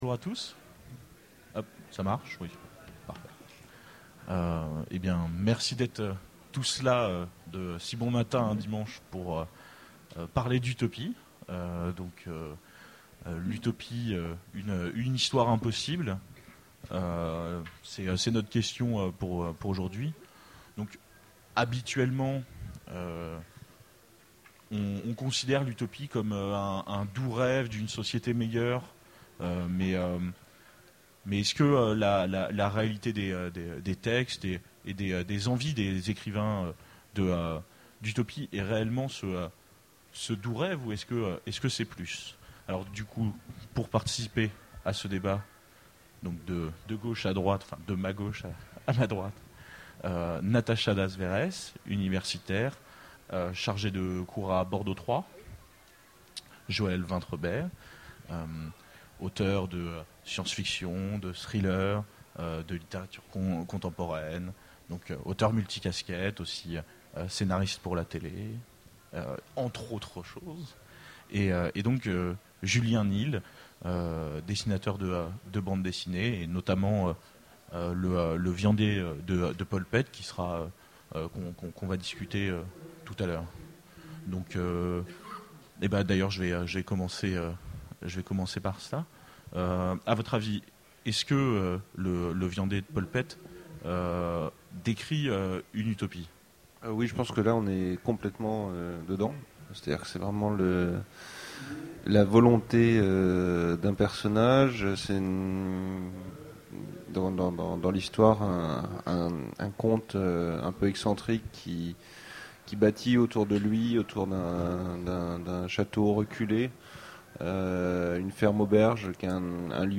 Mots-clés Utopie Conférence Partager cet article